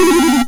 monster_death.mp3